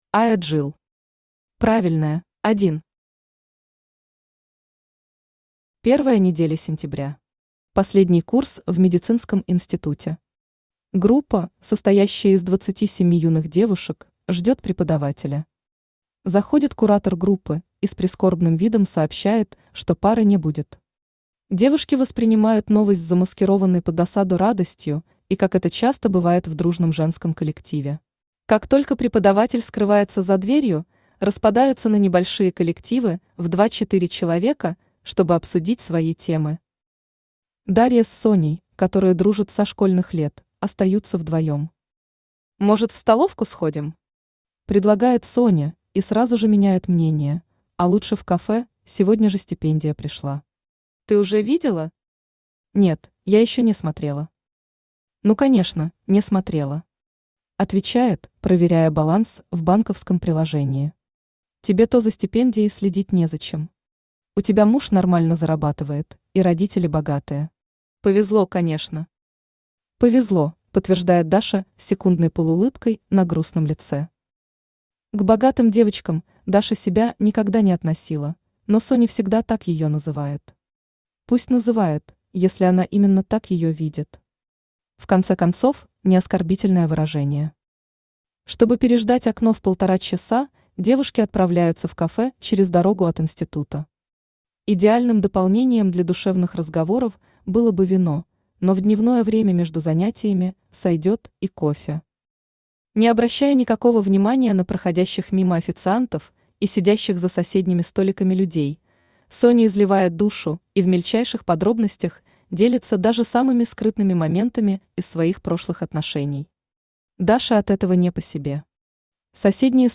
Аудиокнига Правильная | Библиотека аудиокниг
Aудиокнига Правильная Автор Айа Джил Читает аудиокнигу Авточтец ЛитРес.